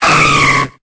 Cri de Cadoizo dans Pokémon Épée et Bouclier.